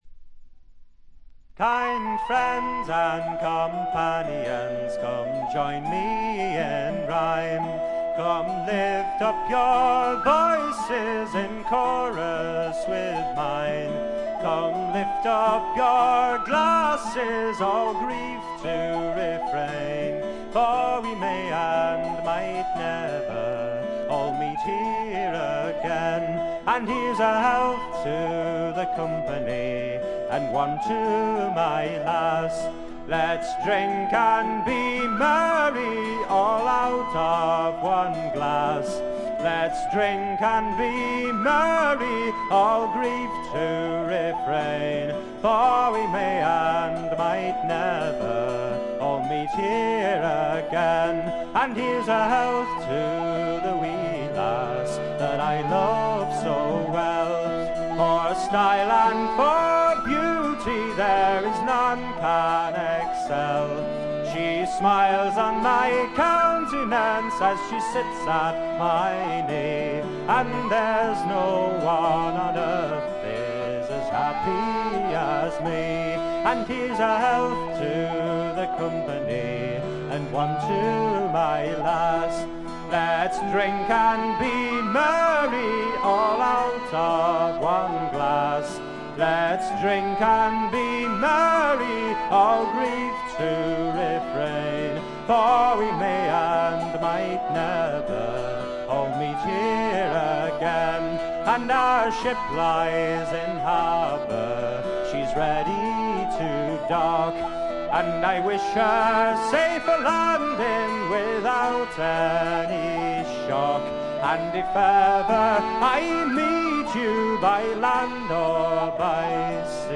ごくわずかなノイズ感のみ。
アコースティック楽器のみで、純度の高い美しい演奏を聴かせてくれる名作です。
試聴曲は現品からの取り込み音源です。